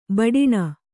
♪ baḍiṇa